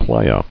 [pla·ya]